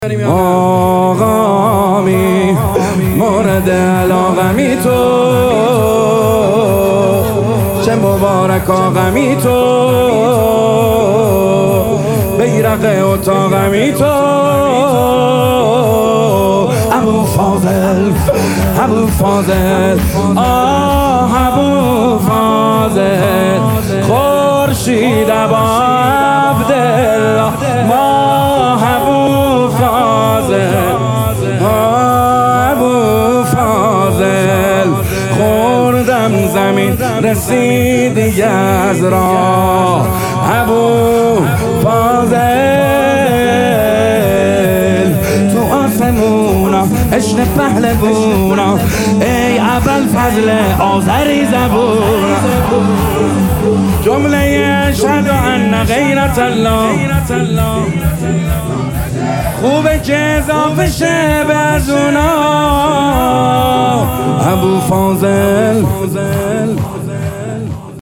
آقامی موردعلاقمی تو چه مبارکا غمی تو - شور شب پنجم فاطمیه